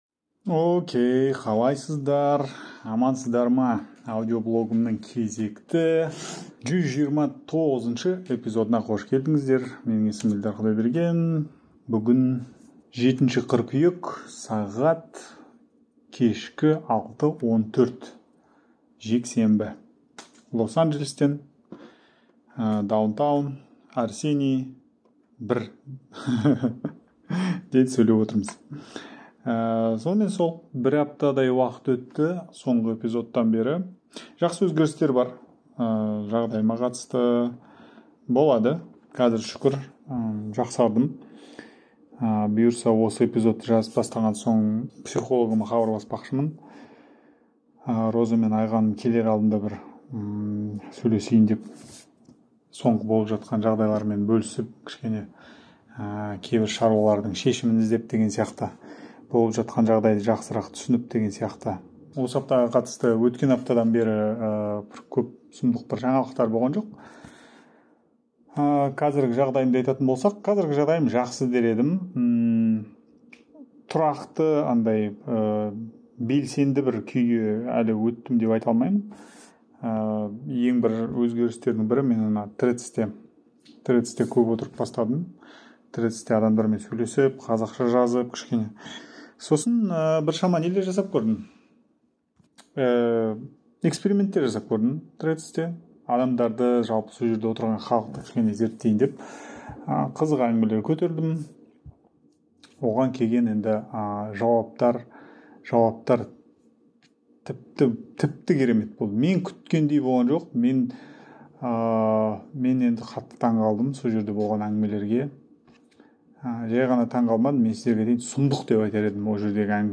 Jazz Trio - Jazz Background Music.